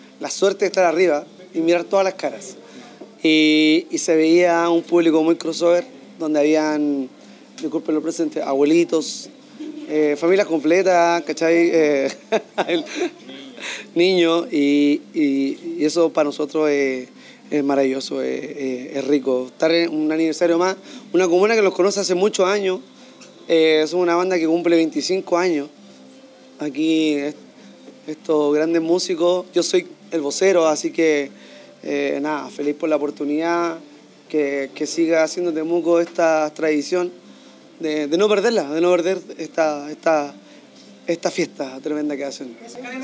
Kanela-vocalista-Noche-de-brujas.mp3